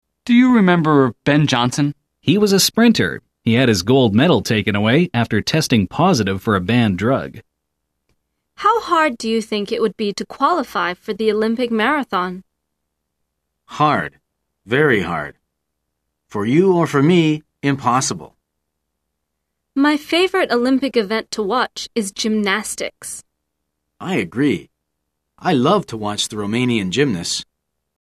來聽老美怎麼說？